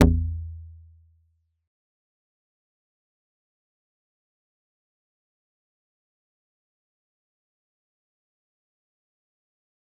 G_Kalimba-A1-mf.wav